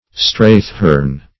Straighthorn \Straight"horn`\, n.